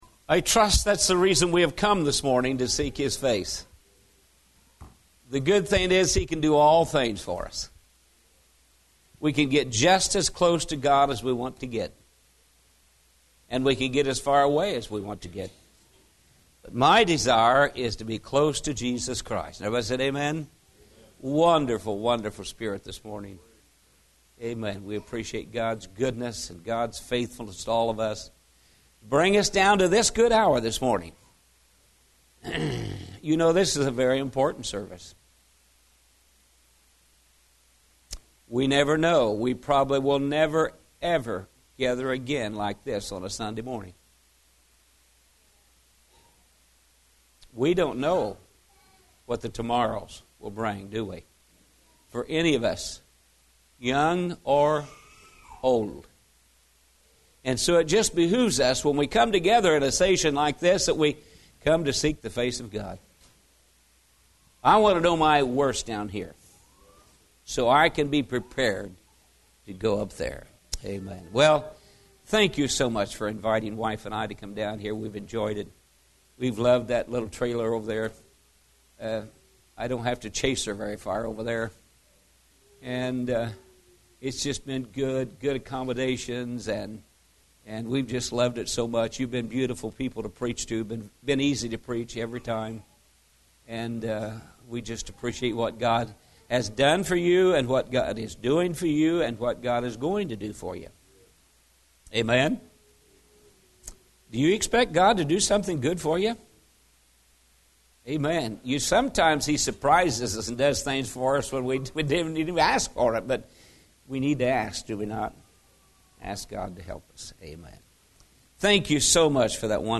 Series: Spring Revival 2017 Tagged with eternal life , God's love , gospel , salvation